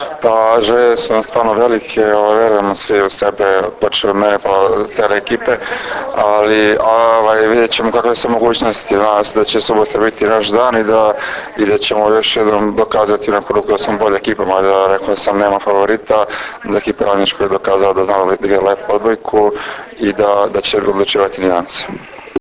U pres sali SD Crvena zvezda danas je održana konferencija za novinare povodom Finalnog turnira 46. Kupa Srbije u konkurenciji odbojkaša.
IZJAVA